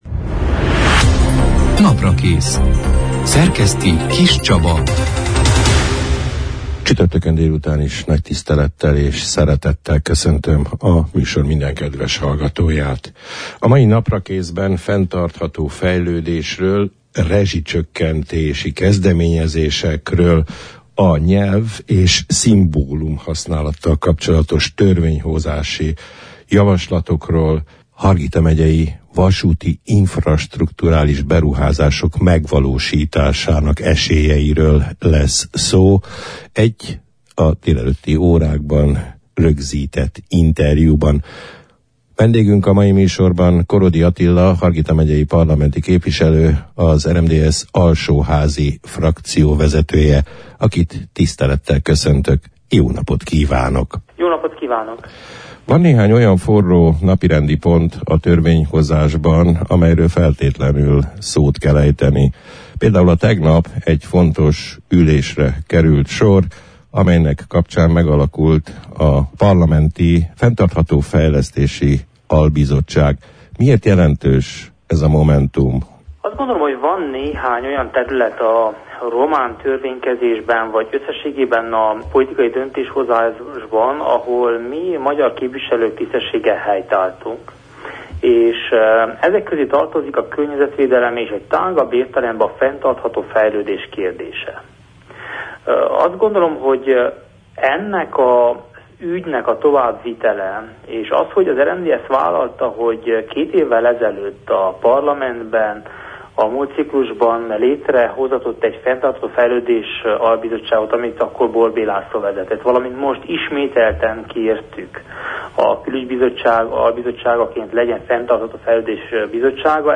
Fenntartható fejlesztési stratégiákról, újabb rezsicsökkentési kezdeményezésekről, nyelvi jogokról és szimbólumhasználati törvénykezdeményezésekről, Hargita megyei infrastrukturális beruházások megvalósítási esélyeiről beszélgettünk a március 23 – án, csütörtökön elhangzott Naprakész műsorban Korodi Attila Hargita megyei parlamenti képviselővel, az RMDSZ alsóházi frakcióvezetőjével.